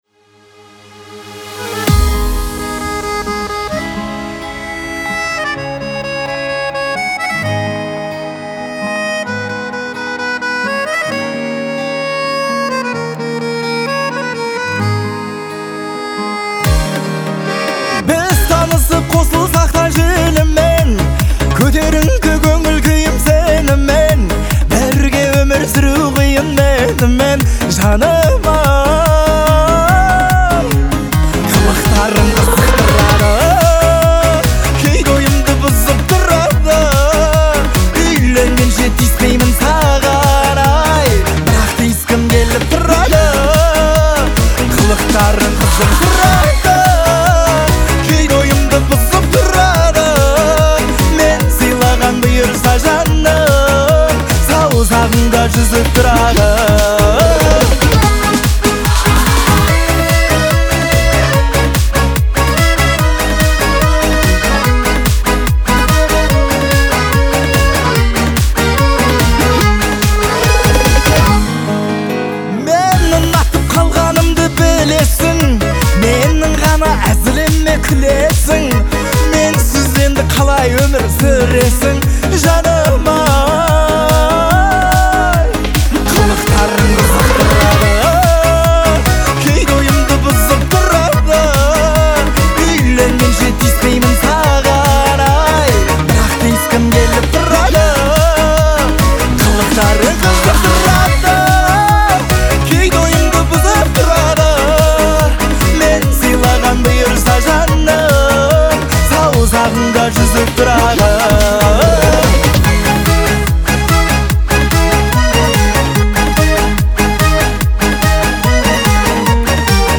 это яркий пример казахского поп-фолка